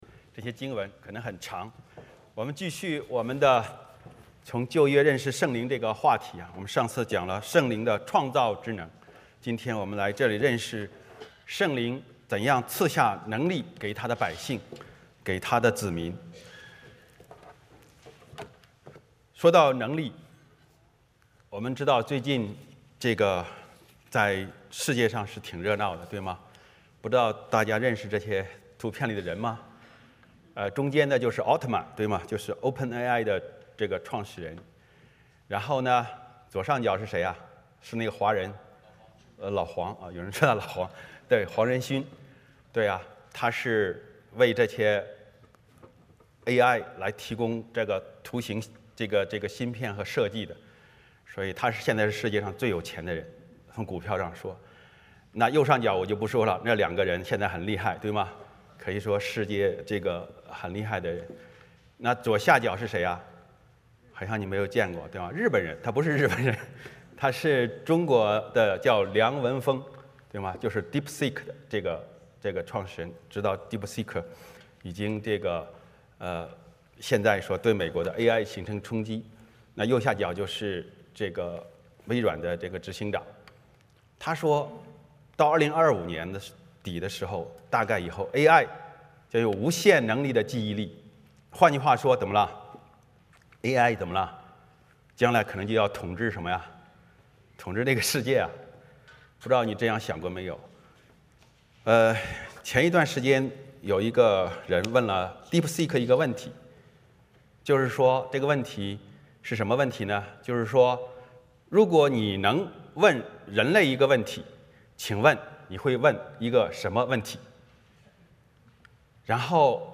欢迎大家加入我们国语主日崇拜。
7； 哥林多后书12:9 Service Type: 主日崇拜 欢迎大家加入我们国语主日崇拜。